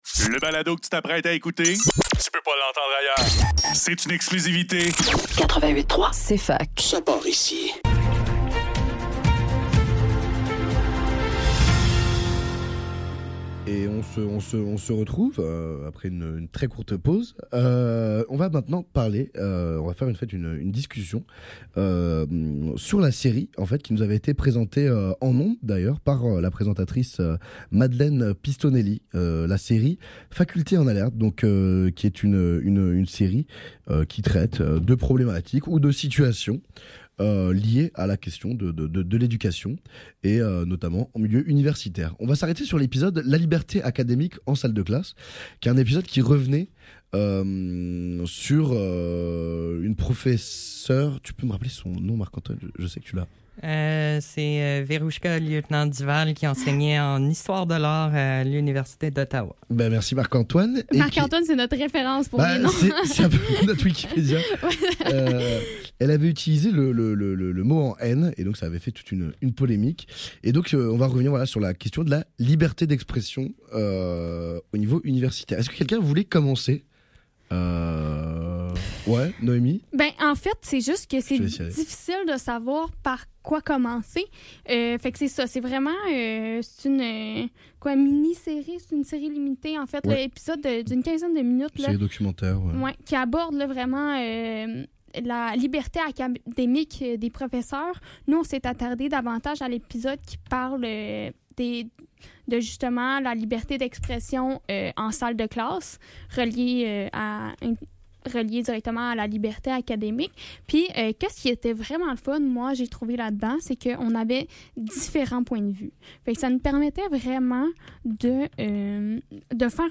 Le NEUF - Discussion sur la série Facultés En Alerte, épisode : La liberté acédémique en salle de classe - 3 avril 2024